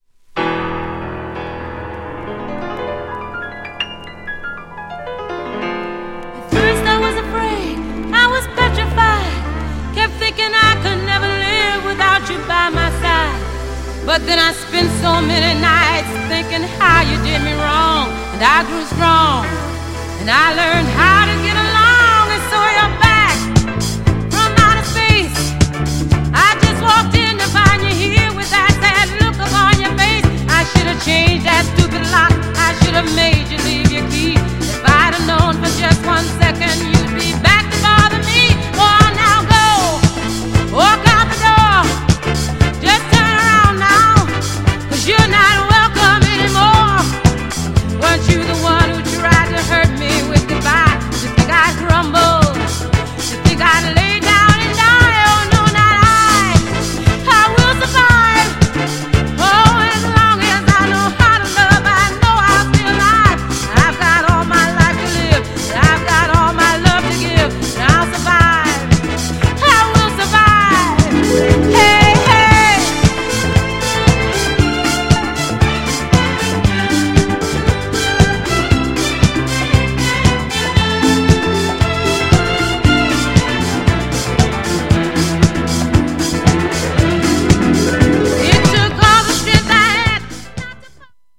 GENRE Dance Classic
BPM 126〜130BPM